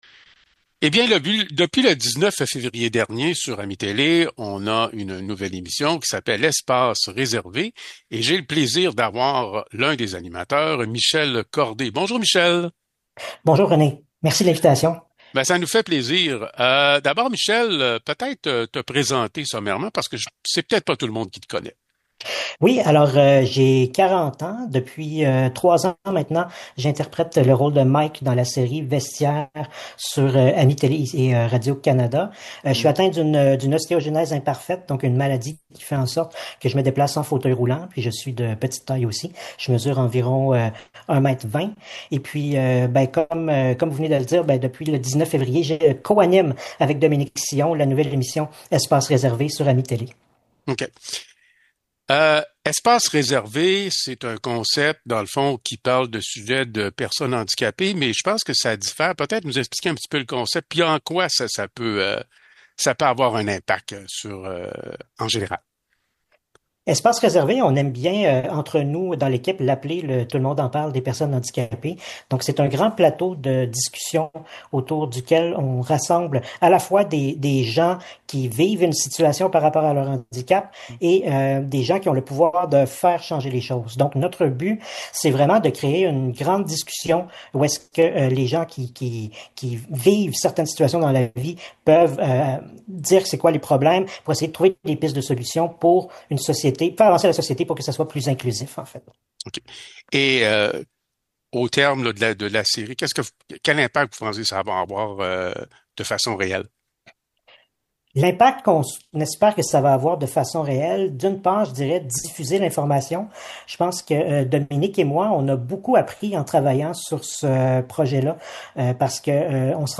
Nous continuons nos entrevues avec les artisans d’AMI-Télé.